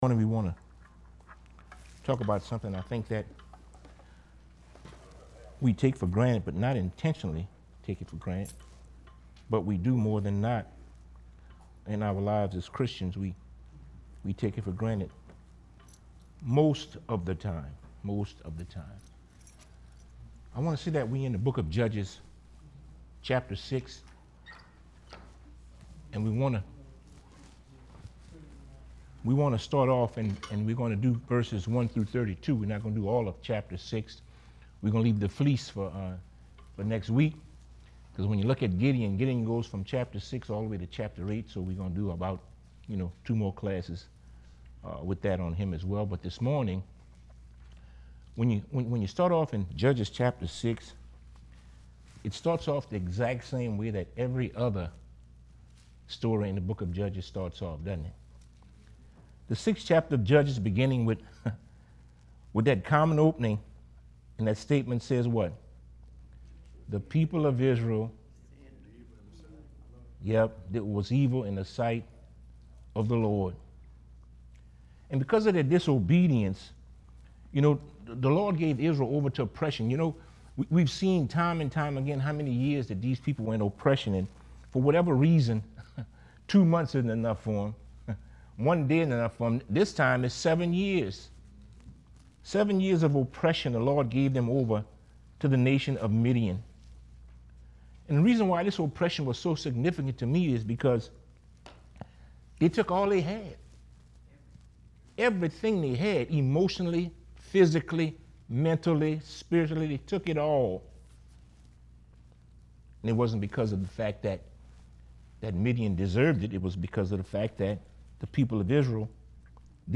bible class